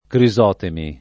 Crisotemi [ kri @0 temi ]